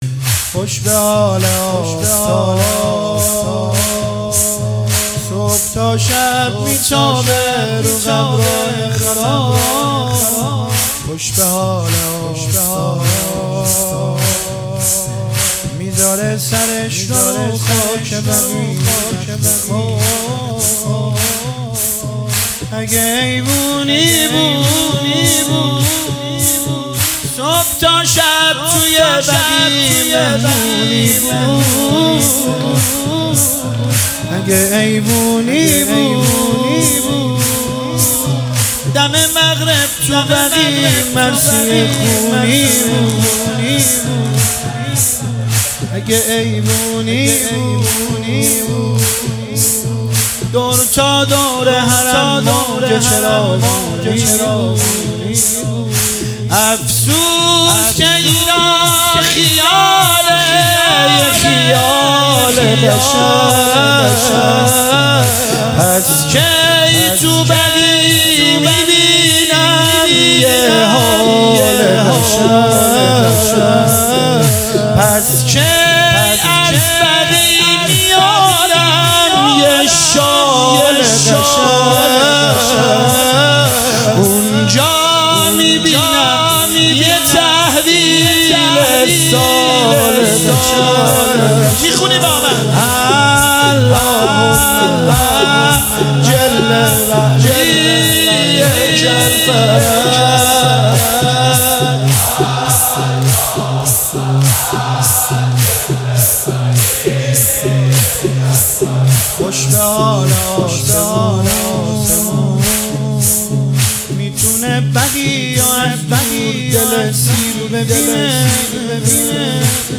مداحی
سالروز تخریب قبور ائمه بقیع 1402